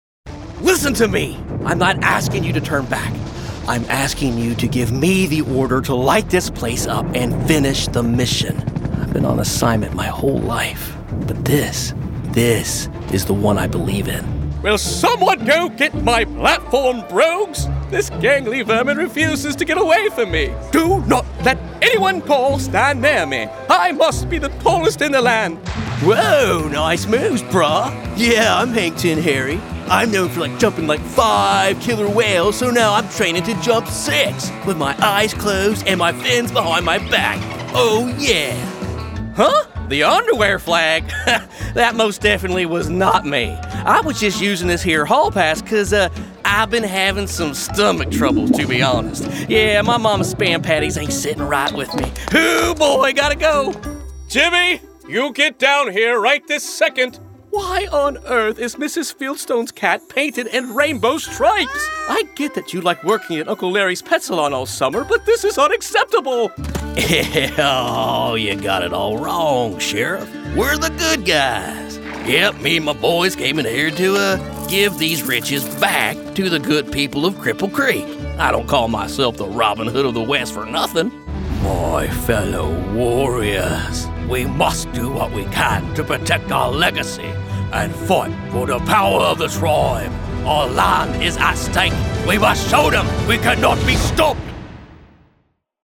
Male
American English (Native)
Confident, Cool, Engaging, Warm, Witty, Versatile
General American-Mid West (Native), American Southern
Microphone: Sennheiser MKH416 & Neumann TLM 102
Audio equipment: Apollo Twin X interface, Broadcast Quality Home Studio